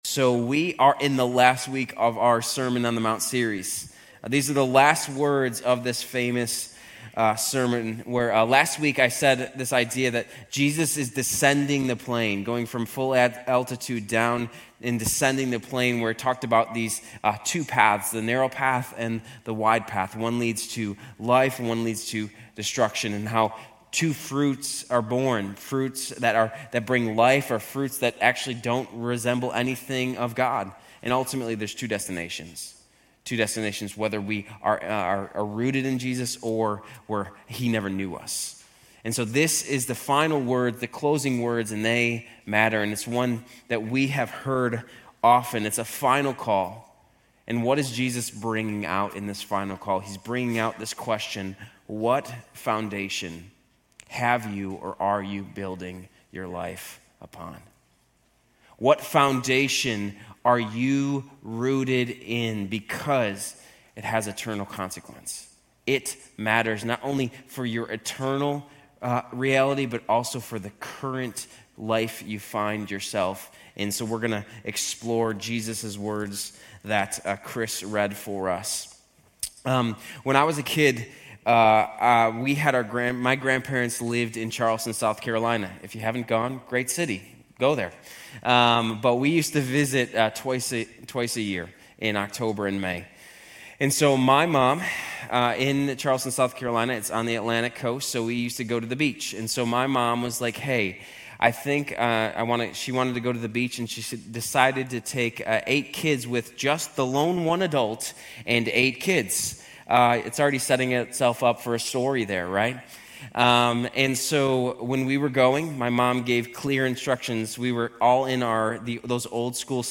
Grace Community Church University Blvd Campus Sermons 4_6 University Blvd Campus Apr 07 2025 | 00:28:11 1x 00:00 / 00:28:11 Subscribe Share RSS Feed Share Link Embed